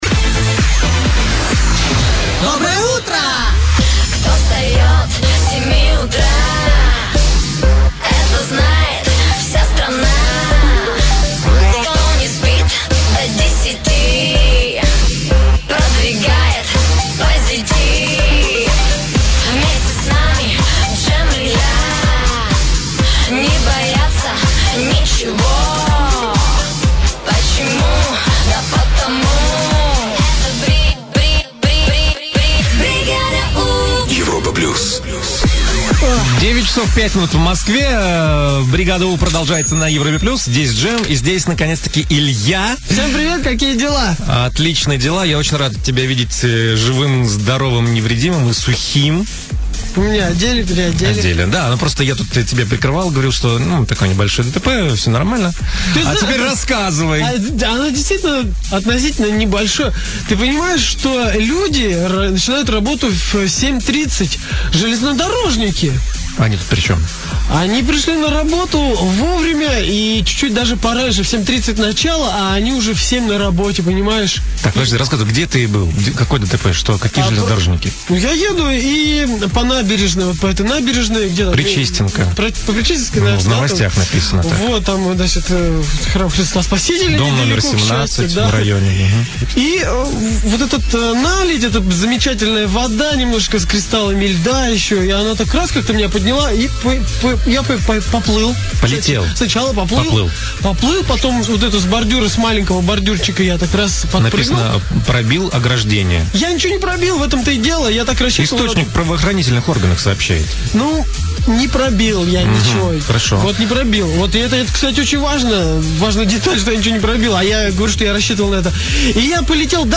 Запись эфира.